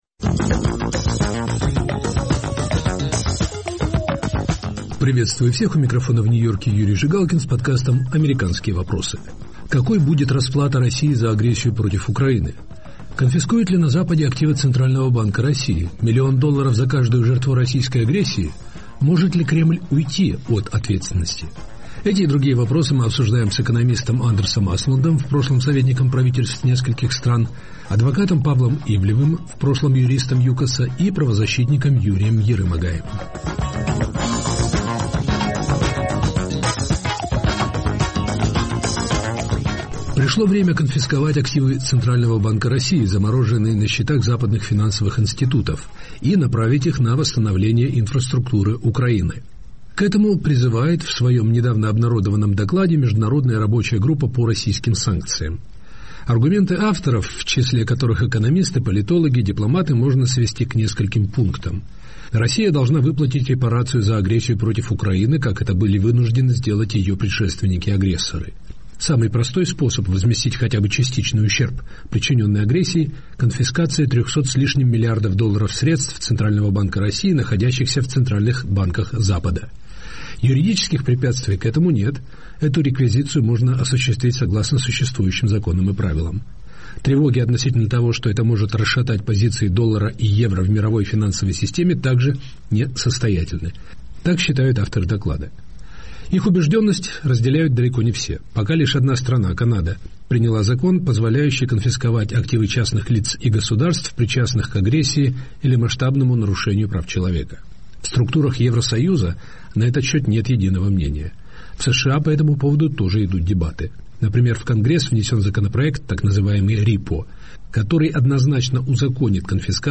Эти и другие вопросы в студии Радио Свобода обсуждают экономист Андерс Аслунд, в прошлом советник...